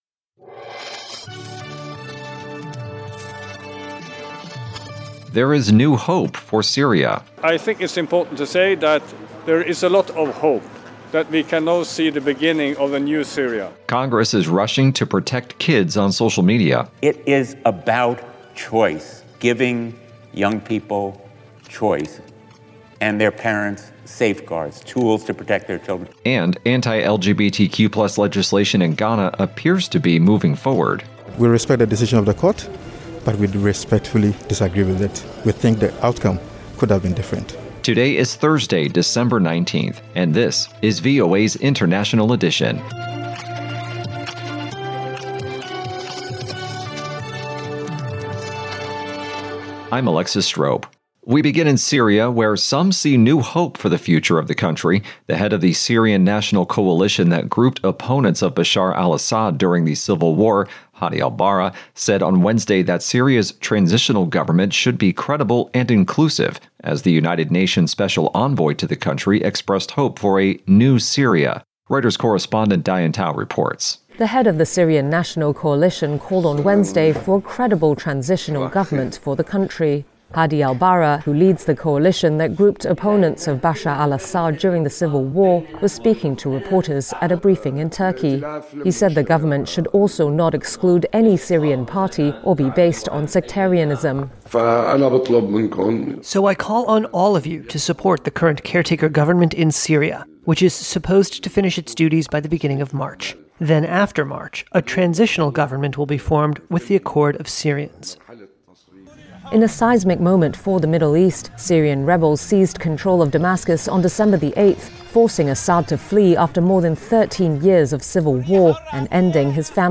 International Edition is VOA's premier global news podcast. Immerse yourself in the latest world events as we provide unparalleled insights through eye-witness accounts, correspondent reports, and expert analysis.